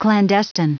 Prononciation du mot clandestine en anglais (fichier audio)
Prononciation du mot : clandestine